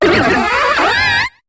Cri de Mustéflott dans Pokémon HOME.